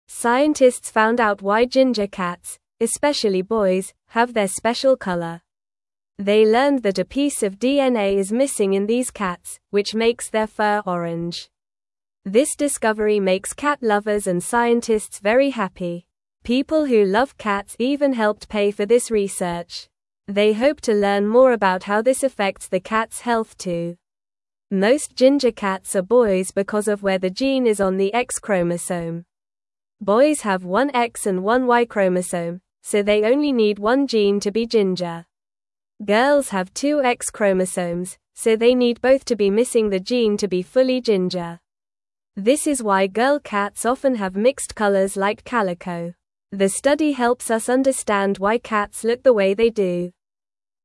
Normal
English-Newsroom-Beginner-NORMAL-Reading-Why-Ginger-Cats-Are-Mostly-Boys-and-Orange.mp3